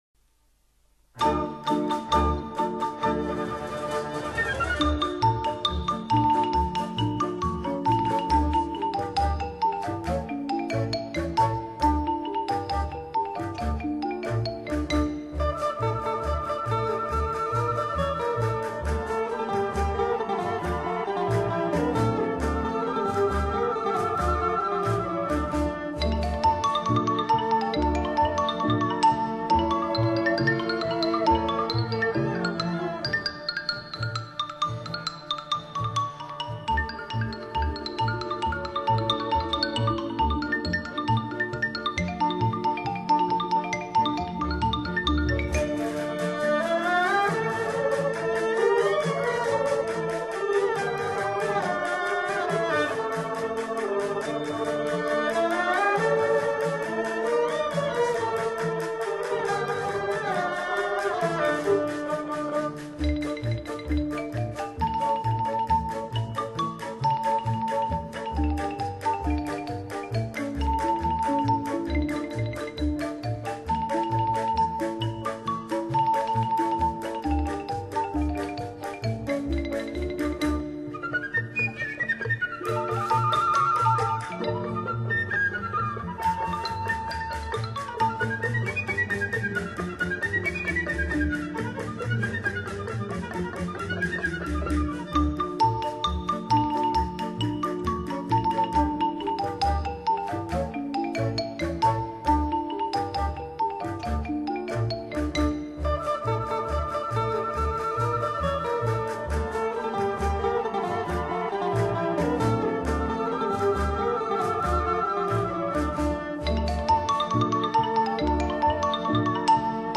木琴与乐队